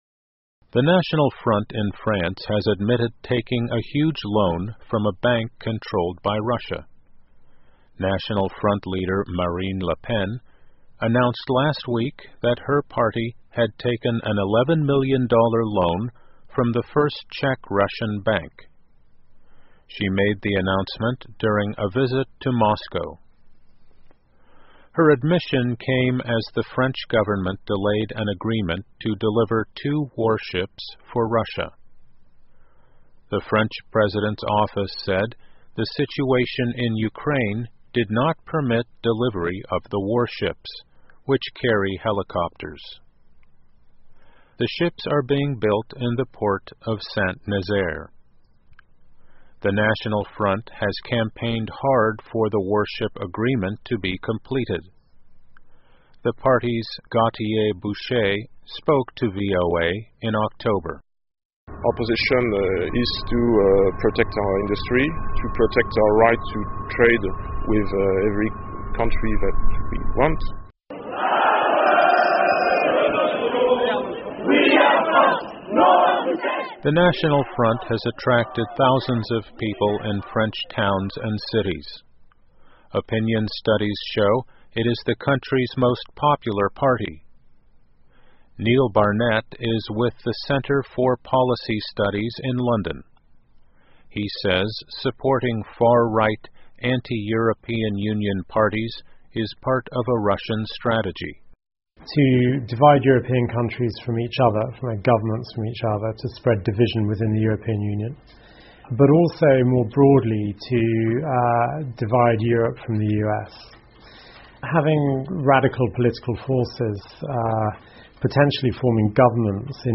在线英语听力室VOA慢速英语2014 法国极右翼党派获得俄罗斯银行900万欧元的贷款的听力文件下载,2014年慢速英语(十二)月-在线英语听力室